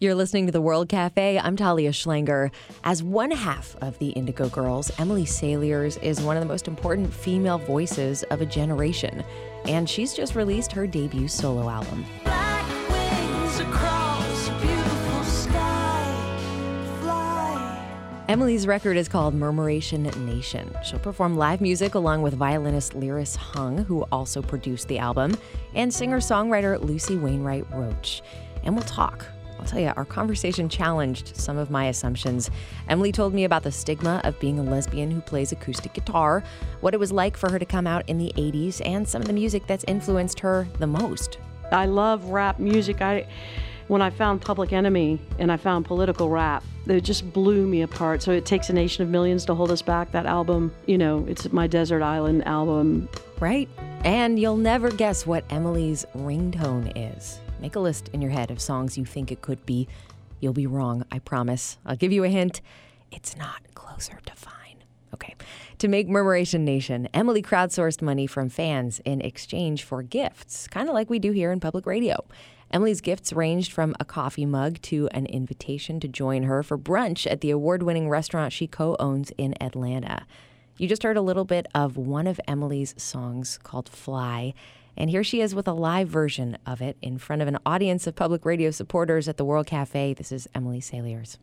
(captured from an online radio broadcast)
01. introduction (1:31)